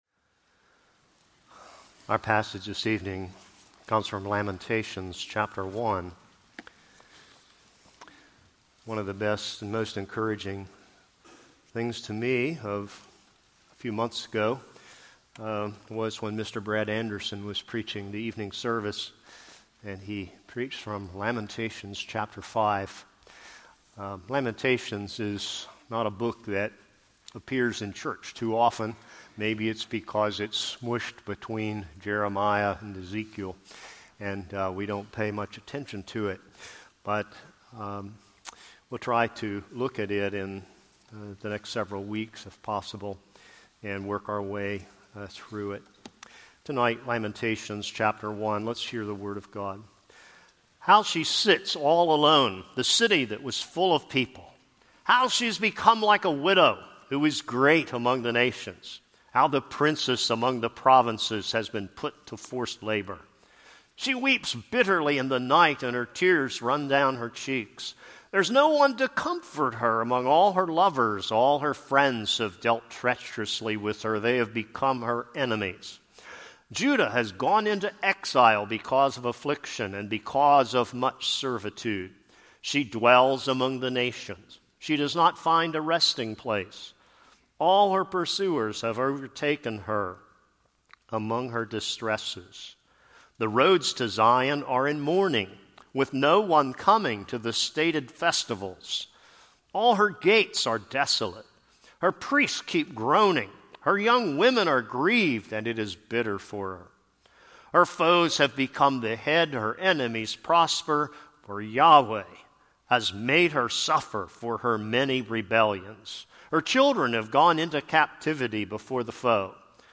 This is a sermon on Lamentations 1.